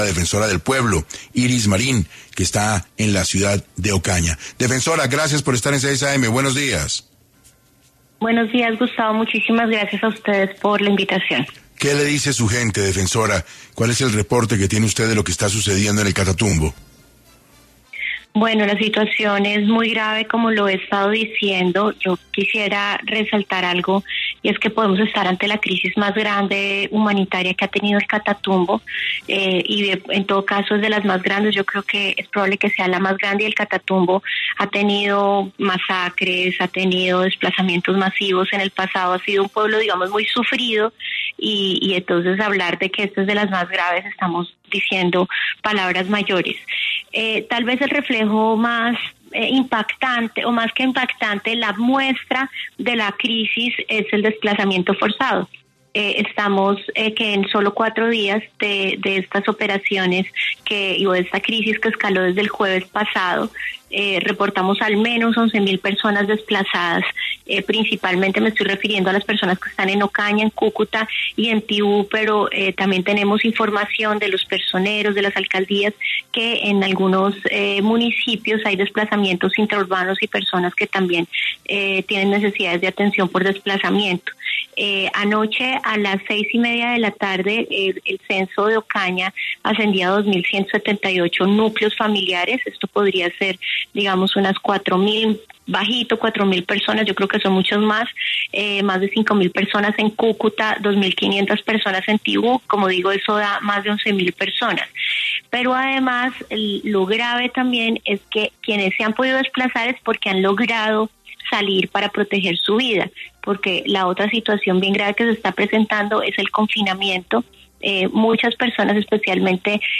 Iris Marín, defensora del Pueblo y Jorge Acevedo, alcalde de Cúcuta, hablaron en 6AM sobre cuántas víctimas dejan los combates entre disidencias y el ELN y qué información tienen sobre los firmantes secuestrados